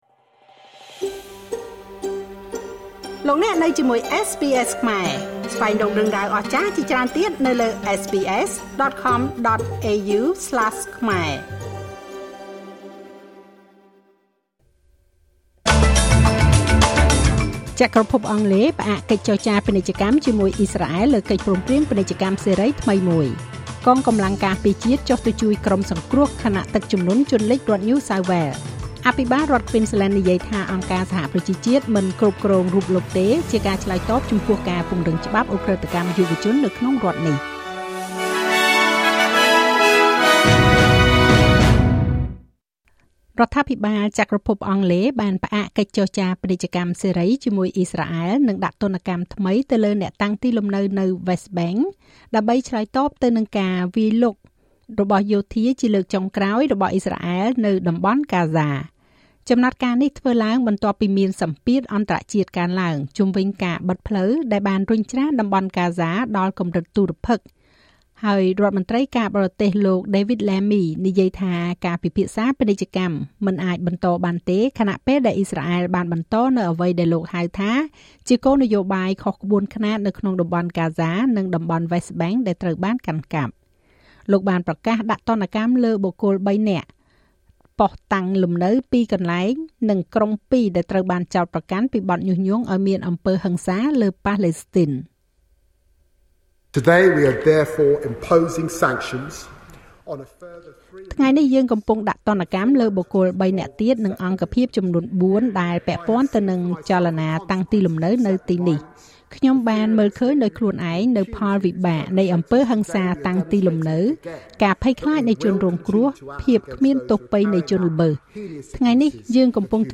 នាទីព័ត៌មានរបស់SBSខ្មែរ សម្រាប់ថ្ងៃពុធ ទី២១ ខែឧសភា ឆ្នាំ២០២៥